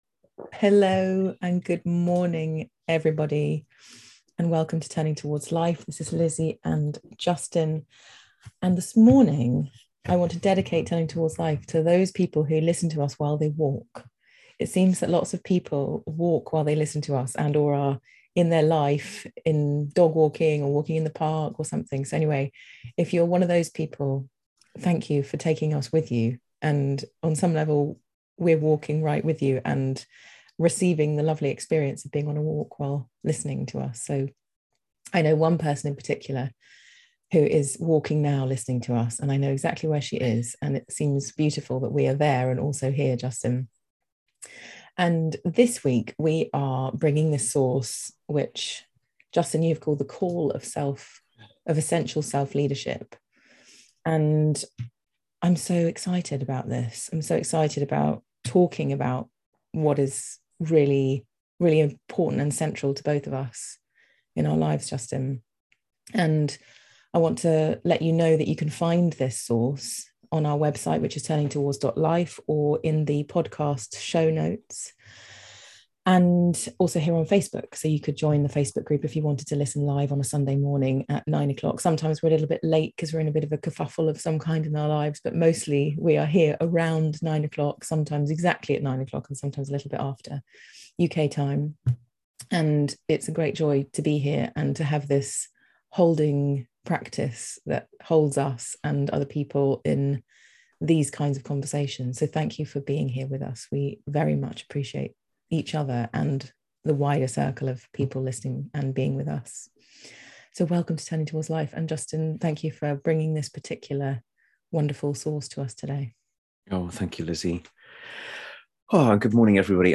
This week's Turning Towards Life is a conversation about how we lead together from in a way that draws upon our essential goodness.